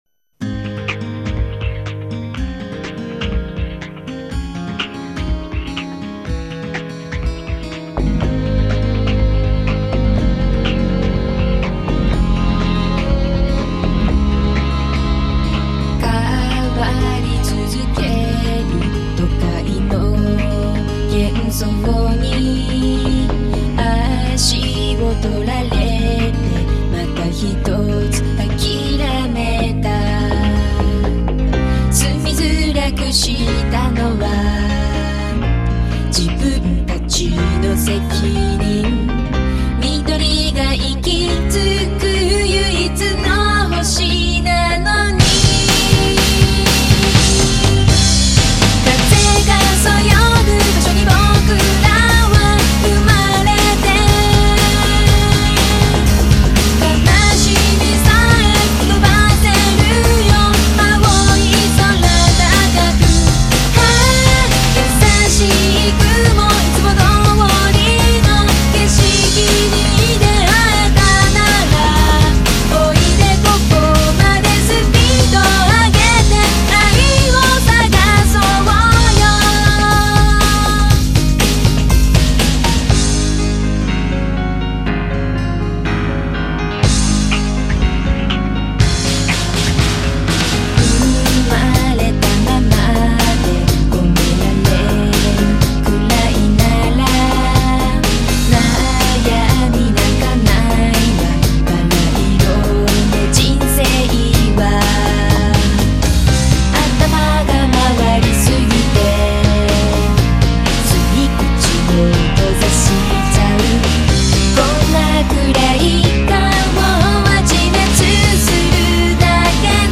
The first Japanese opening theme